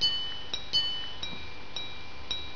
een mechanisch horloge
Een répétition minute is een slagwerk dat uren, kwartieren en minuten slaat.
Het geluid van het slaande horloge is van een ontroerende breekbaarheid.